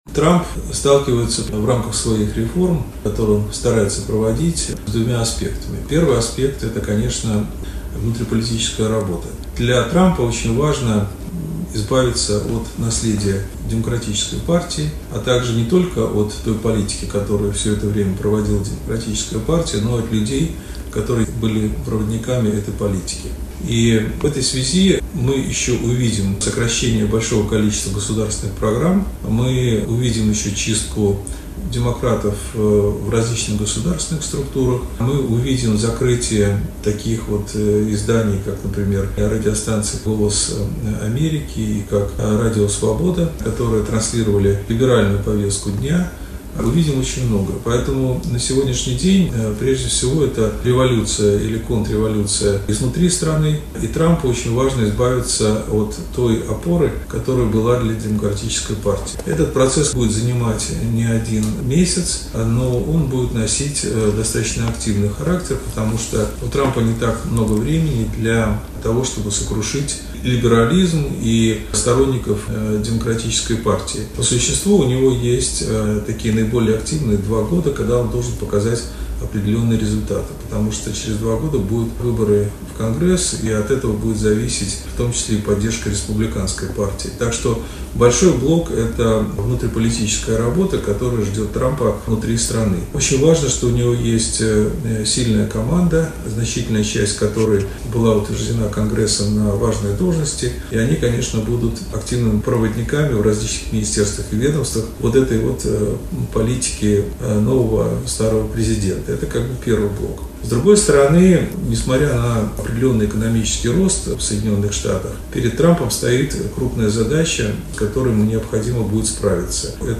ГЛАВНАЯ > Актуальное интервью
Заместитель генерального директора Международной медиагруппы "Россия сегодня", Чрезвычайный и Полномочный Посол Александр Яковенко в интервью журналу «Международная жизнь» рассказал об экономической политике Дональда Трампа: